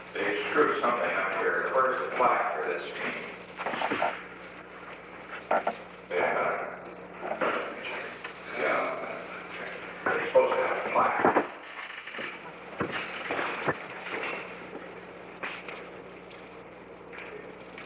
Secret White House Tapes
Conversation No. 662-1
Location: Oval Office
The President met with Alexander P. Butterfield.